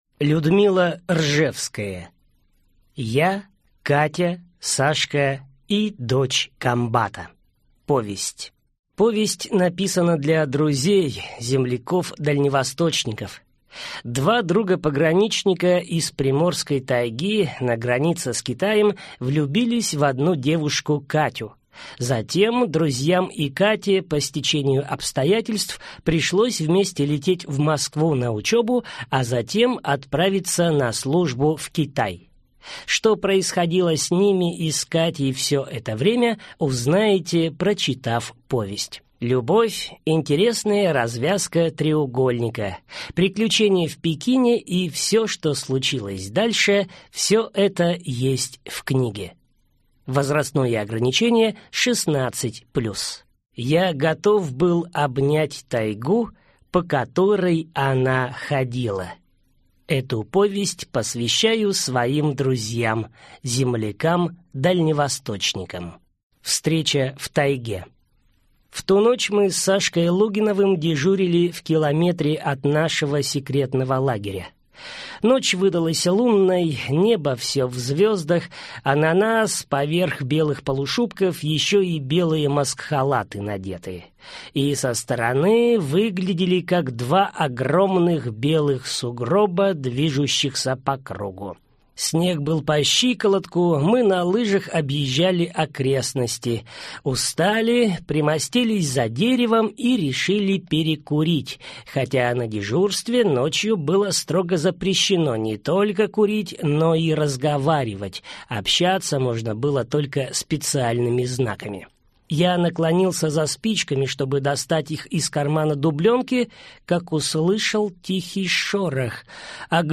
Аудиокнига Я, Катя, Сашка и дочь комбата | Библиотека аудиокниг